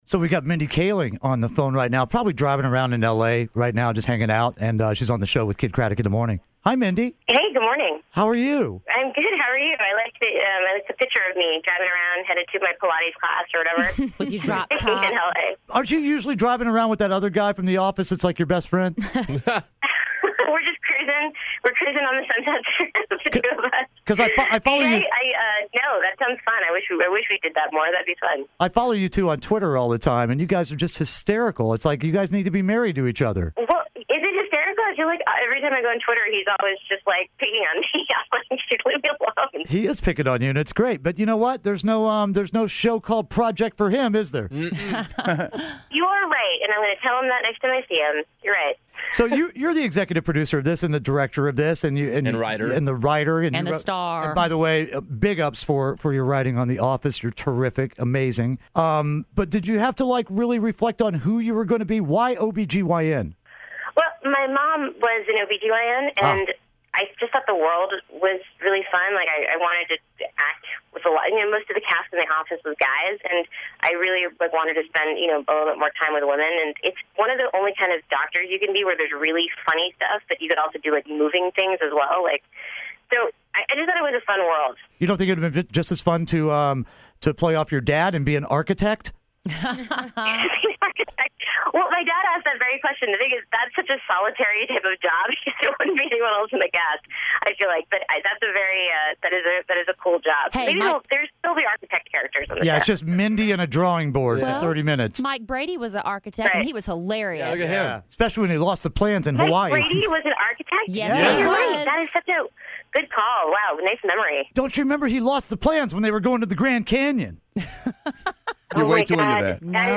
Mindy Kaling Interview
Kidd Kraddick in the Morning interviews Mindy Kaling from The Mindy Project.